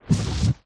hitting_ side.ogg